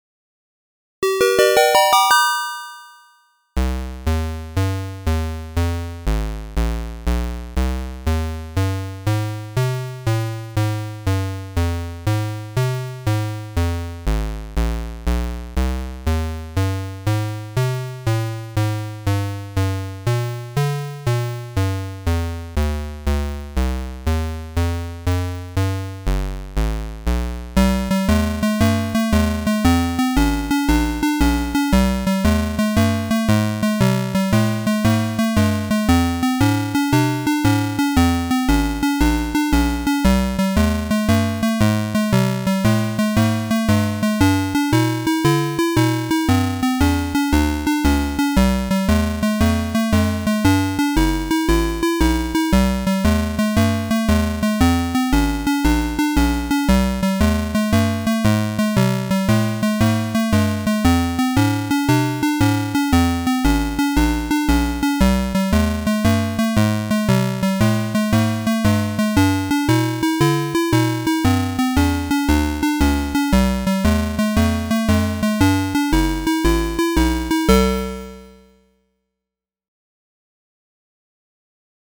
"BLUES"
Atari-ST Emulation